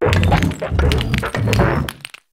naclstack_ambient.ogg